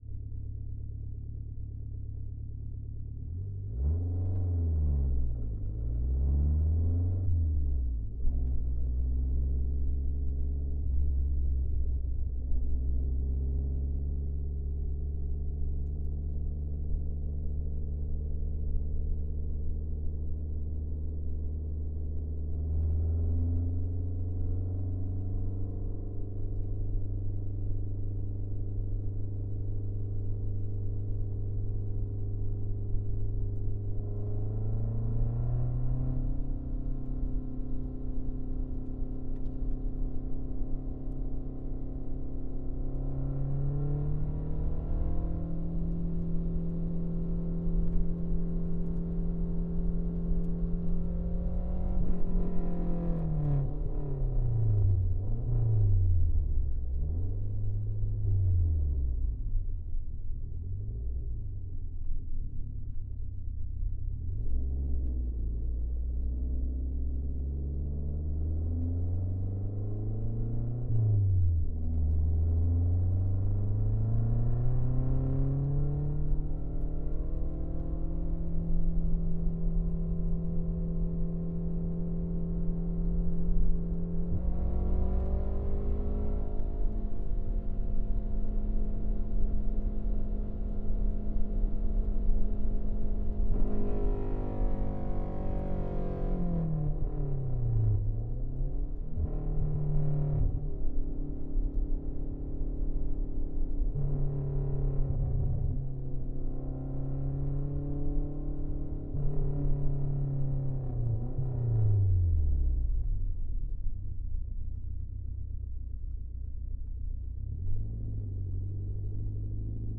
Lancia_Delta_Integrale_t6_Onbrd_Drive_Steady_RPMs_Interior_DPA4021.ogg